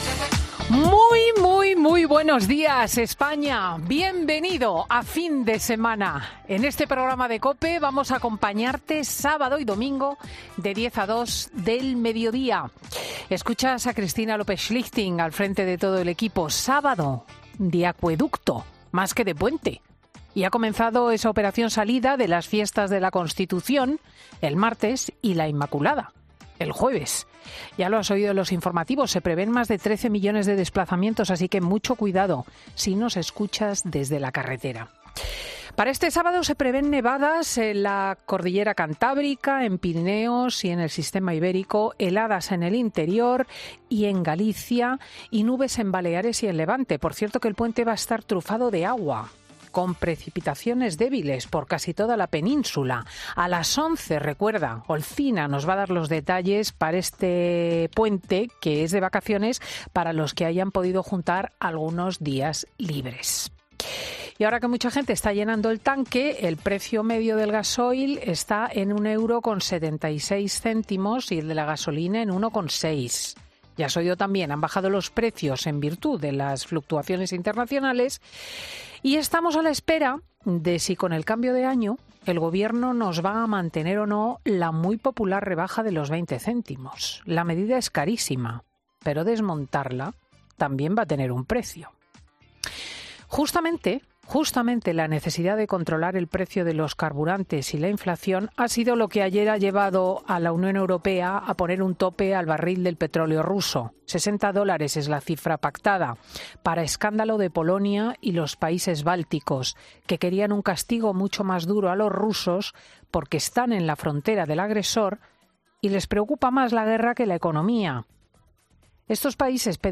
Habla Cristina López Schlichting de todas las artimañas que está llevando a cabo Pedro Sánchez para continuar en el poder